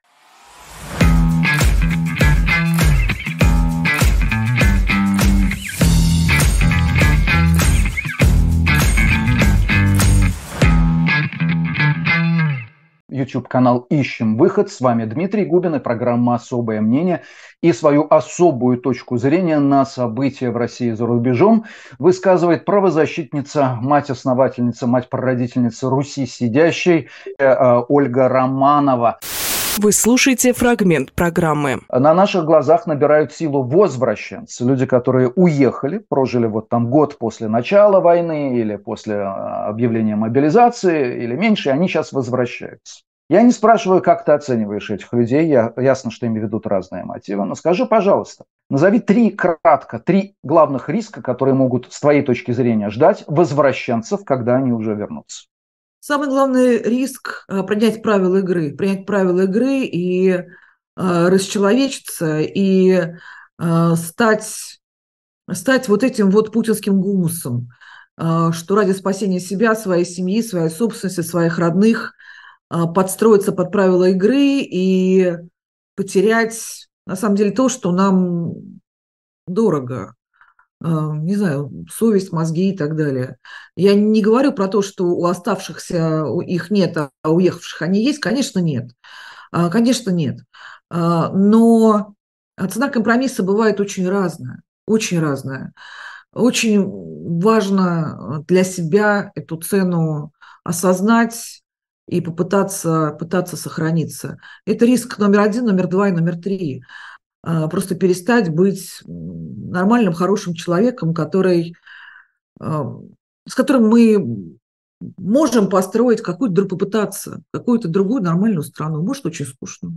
Ольга Романоваправозащитник, директор фонда «Русь сидящая»
Фрагмент эфира от 03.07.23